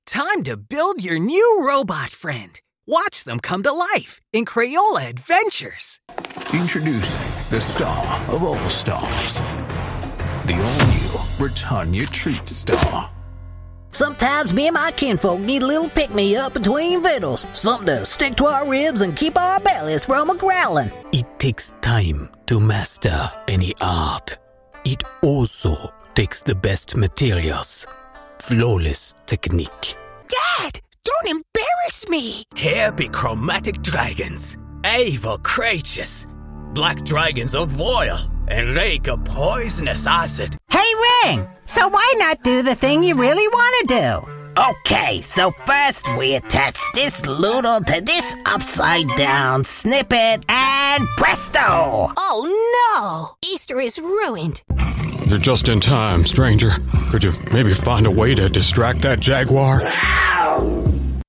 Commercial, Natural, Distinctive, Accessible, Versatile